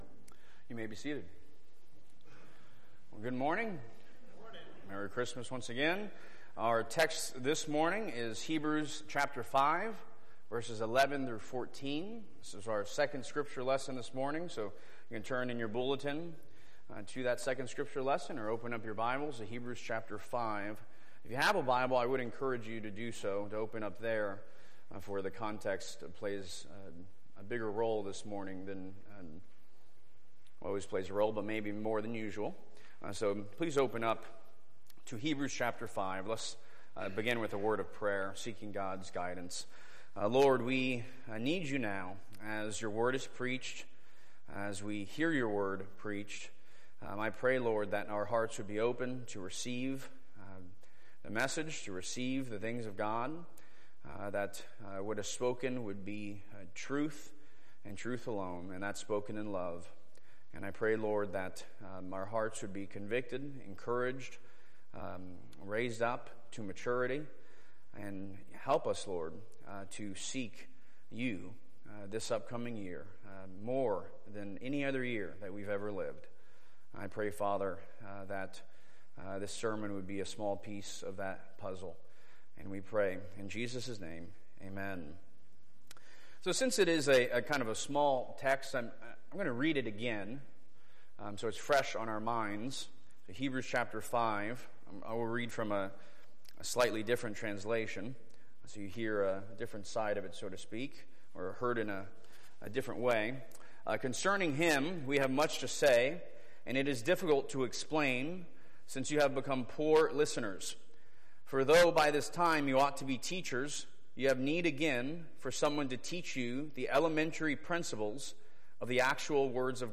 Sermons from the Pulpit of Christ Central PCA